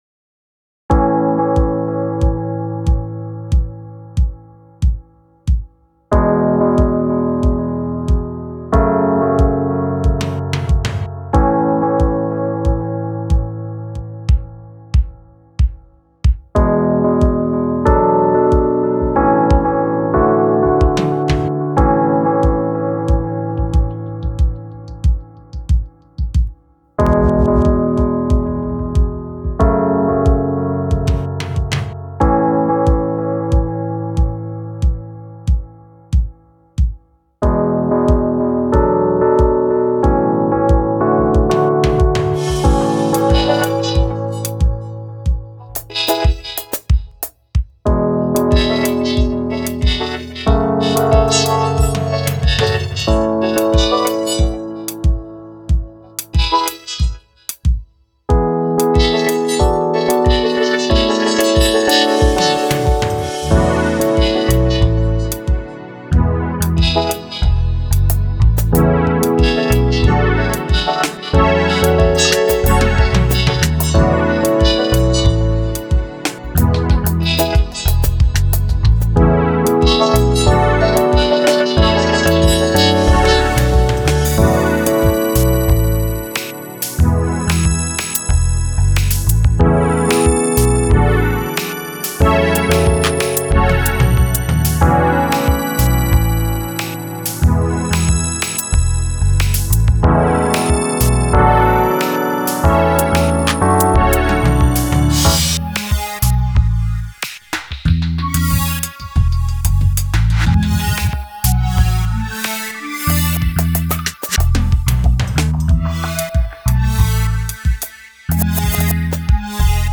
Latin